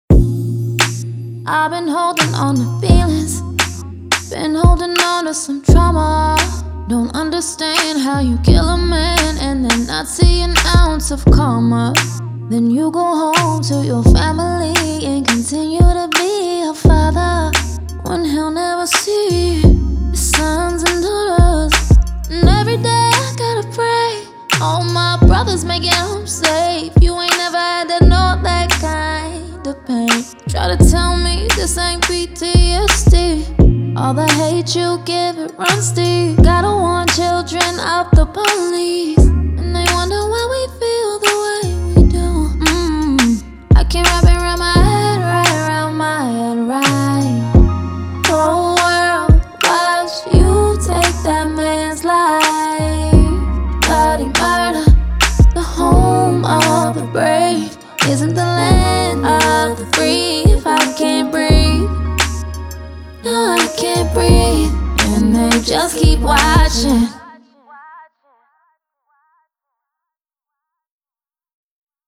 R&B
B# Minor